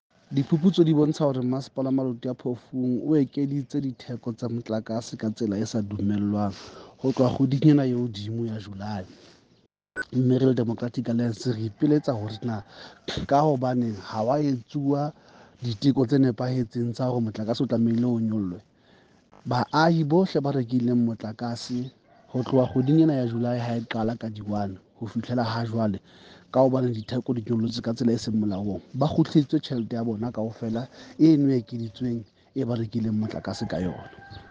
Sesotho by Cllr Moshe Lefuma.